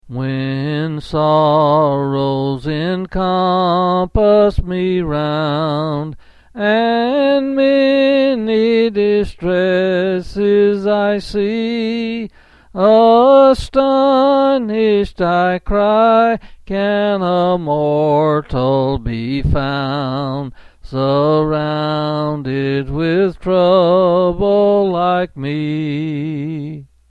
Quill Pin Selected Hymn